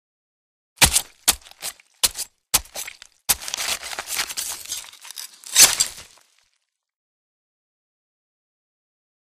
Stab Flesh
Knife Into Flesh: Multiple Quick Stabs; Four Very Fast, Wet Knife Stabs Followed By A Long, Deliberate One. Close Perspective.